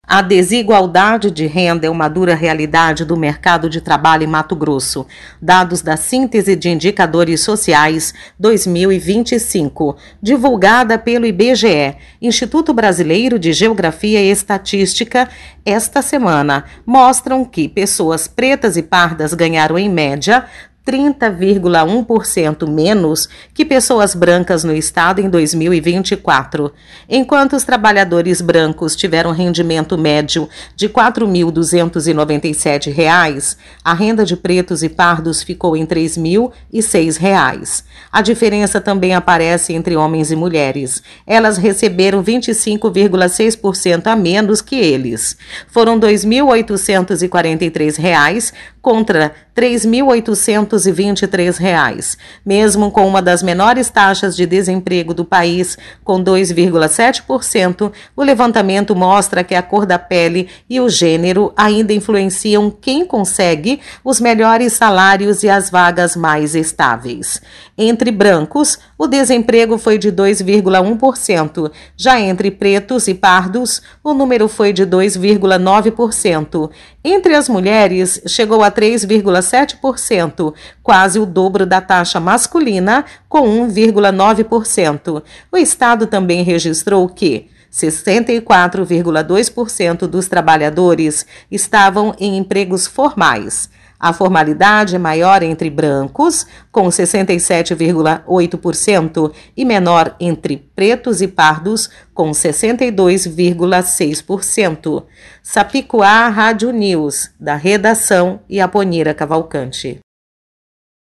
Boletins de MT 05 dez, 2025